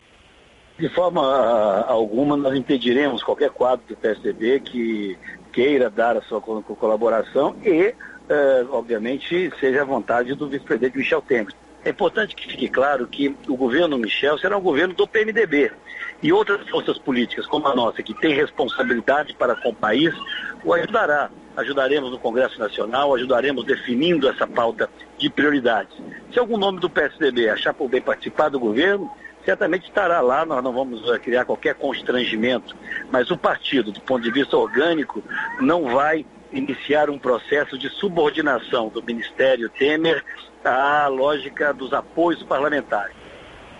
Segue transcrição da entrevista à rádio CBN com áudio anexo.